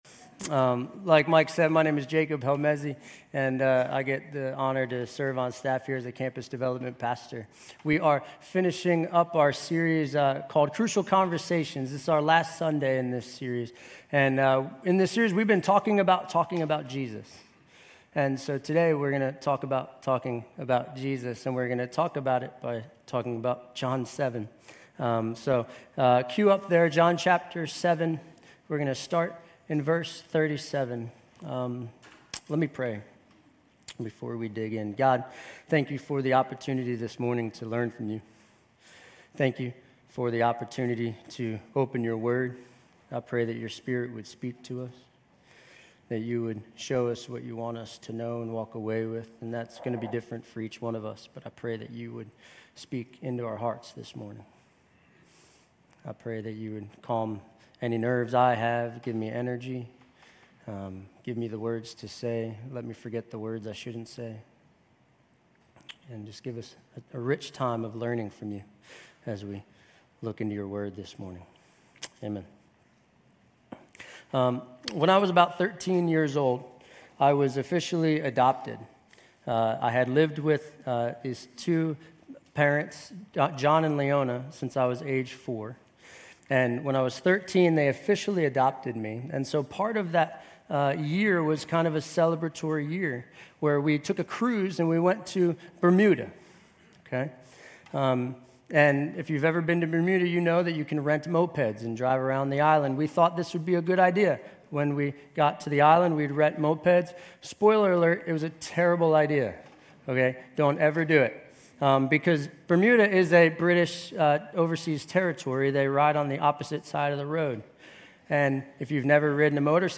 Grace Community Church University Blvd Campus Sermons Crucial Conversations: John 7:45-52 Nov 19 2023 | 00:31:25 1x 00:00 / 00:31:25 Subscribe Share RSS Feed Share Link Embed